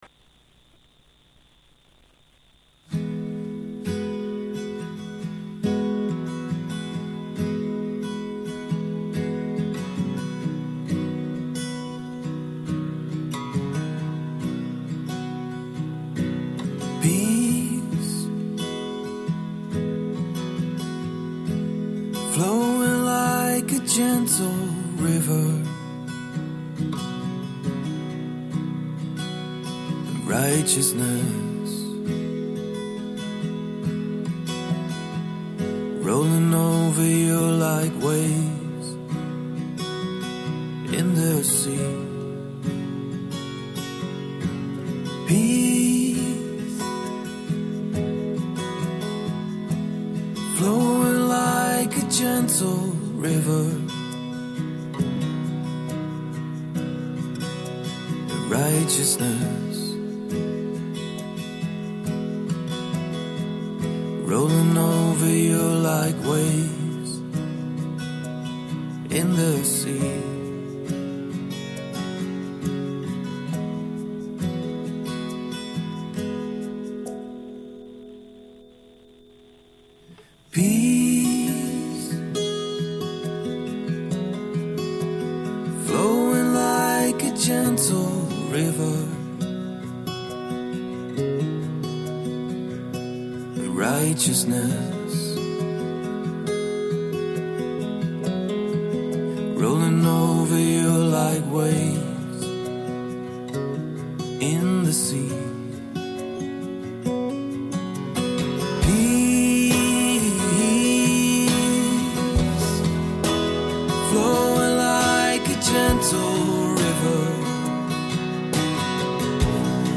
FaithMatters Music and Interviews bring a point of difference